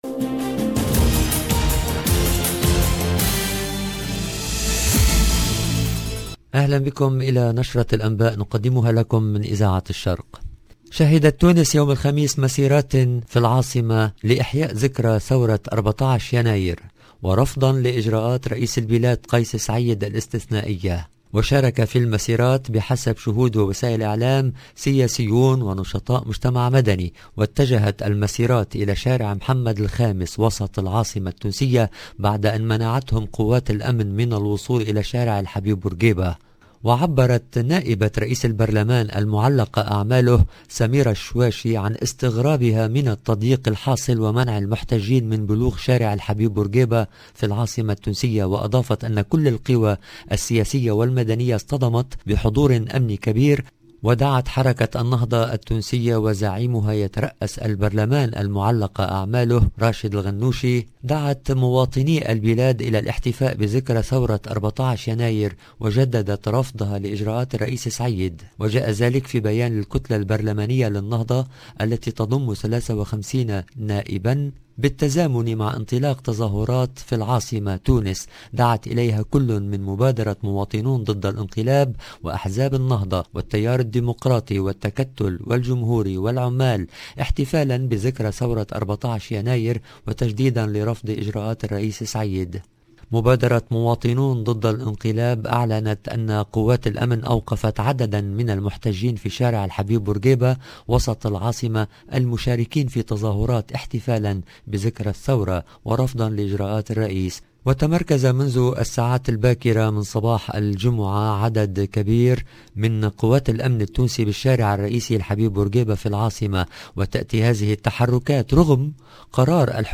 LE JOURNAL DU SOIR EN LANGUE ARABE DU 14/01/22